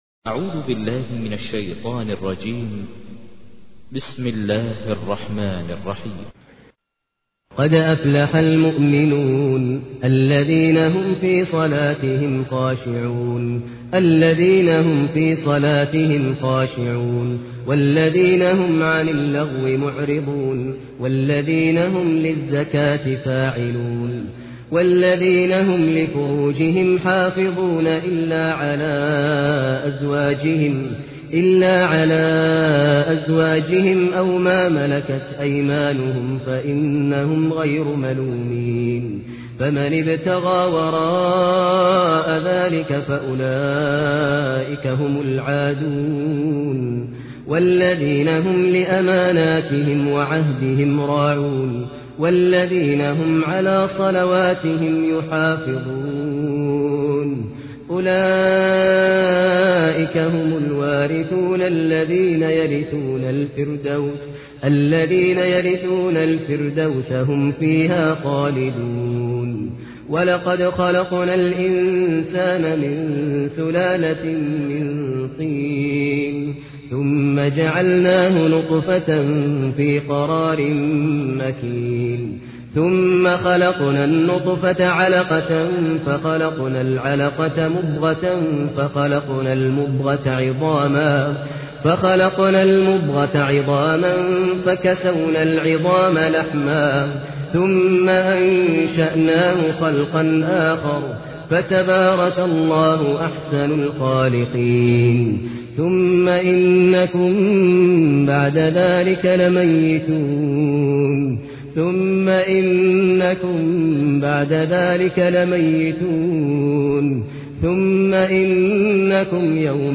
تحميل : 23. سورة المؤمنون / القارئ ماهر المعيقلي / القرآن الكريم / موقع يا حسين